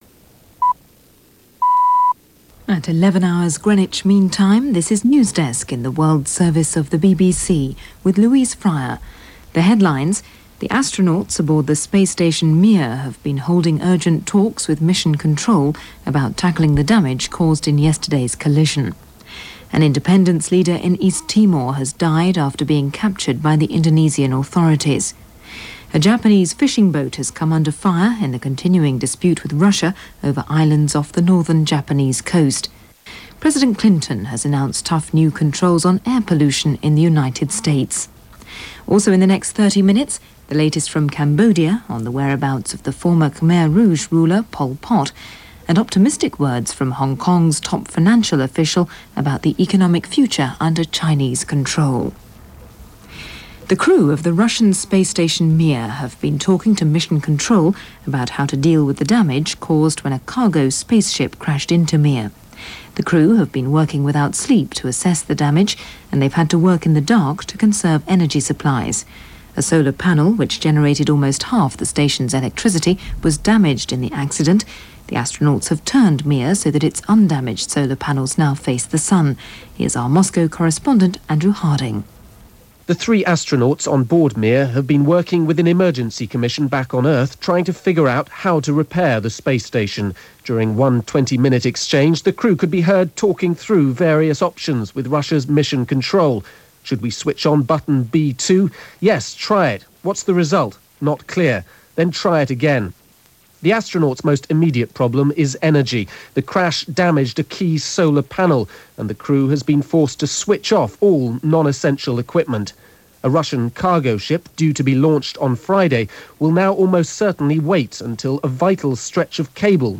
News of the day from all over the world from The BBC World Service.